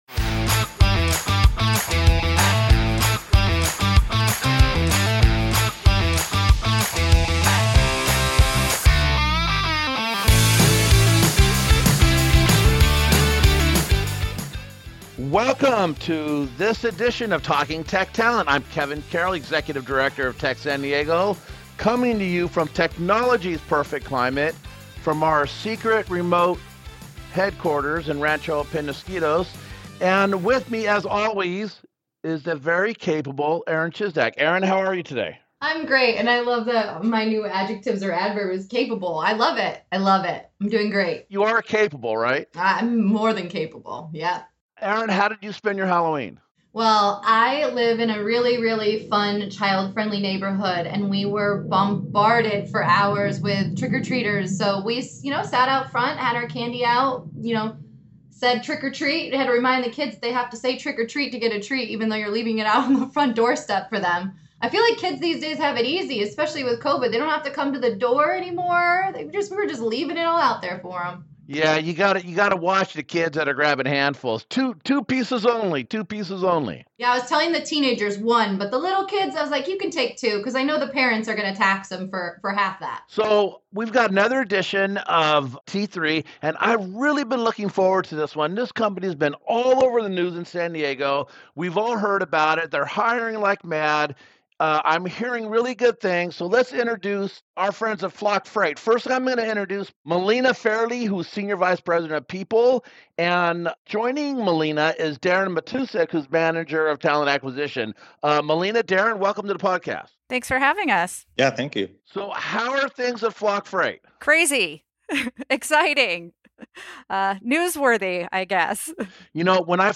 Join us for a fun conversation with Start-Up Unicorn Flock Freight!
T3 is recorded remotely and edited by Hypable Impact Intro/Outro Music